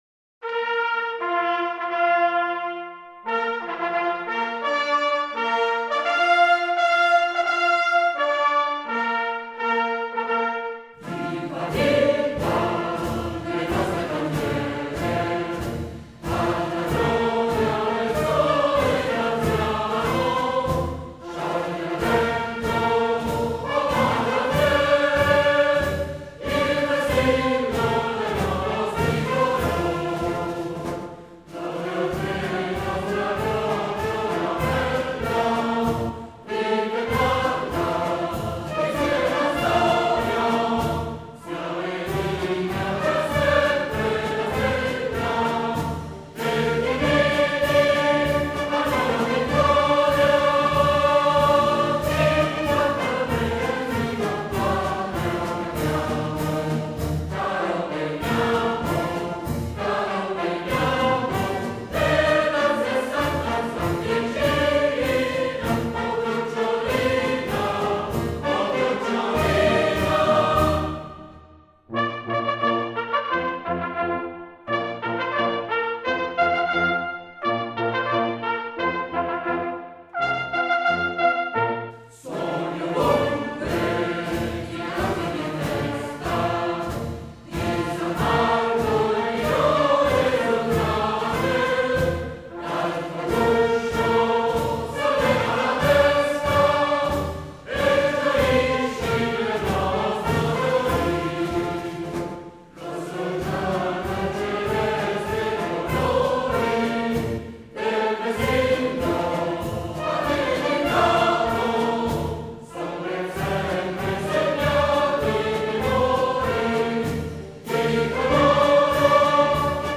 Versi di Bruno Zalaffi, musica del Maestro Giovanni Bonnoli – L’esecuzione dell’inno spesso inizia con alcune battute della marcia del Palio, il movimento è in 2/4, marziale e appoggiato quasi esclusivamente sugli ottoni.